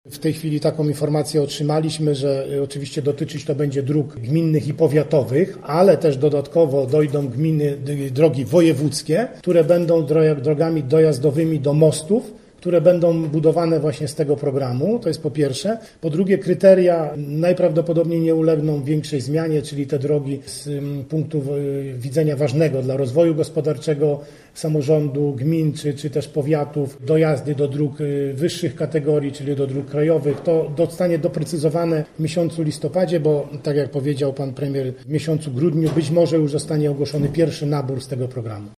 Wojewoda Lubuski Władysław Dajczak zorganizował dziś konferencję podczas której poinformował o przyznaniu dotacji dla dwóch lubuskich inwestycji sportowych.
Wojewoda poinformował także o nowym programie wspierającym remonty dróg lokalnych. Do Funduszu Dróg Samorządowych można będzie składać wnioski już w grudniu tego roku. Mówi Władysław Dajczak